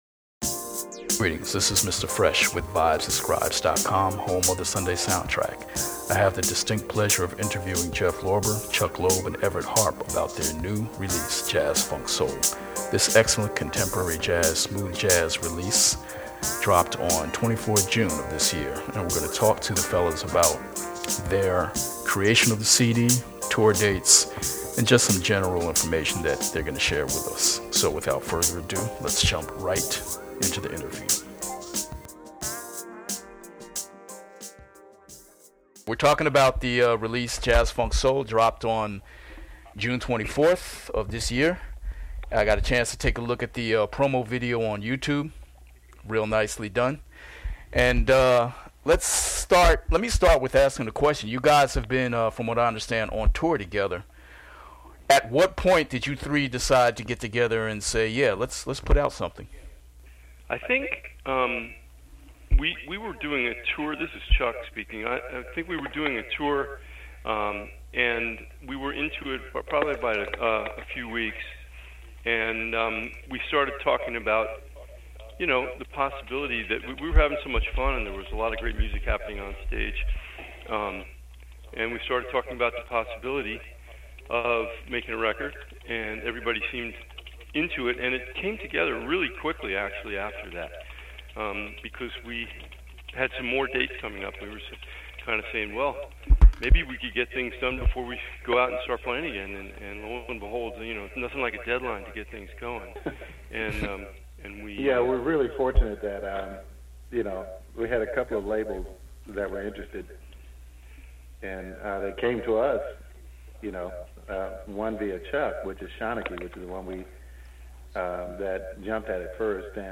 Jazz Funk Soul – An Interview w/Jeff Lorber, Chuck Loeb, & Everette Harp
JFS_Interview.mp3